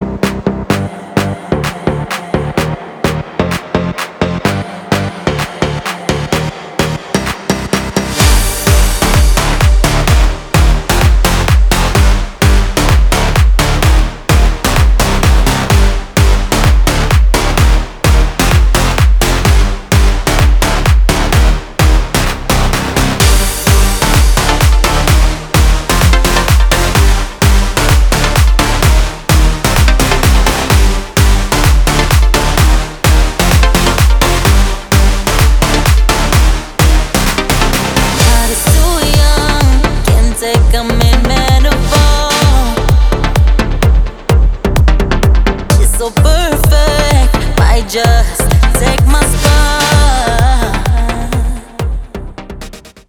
• Качество: 320, Stereo
громкие
заводные
dance
EDM
энергичные
красивый женский голос
electro house